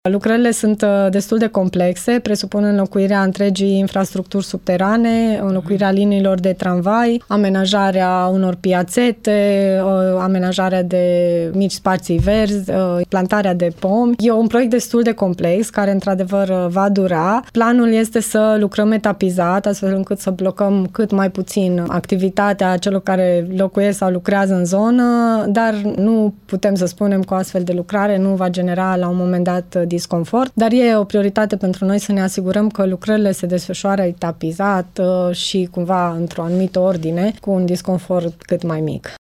Viceprimarul Paula Romocean spune, însă, că intervențiile vor fi efectuate etapizat.
Paula-Romocean.mp3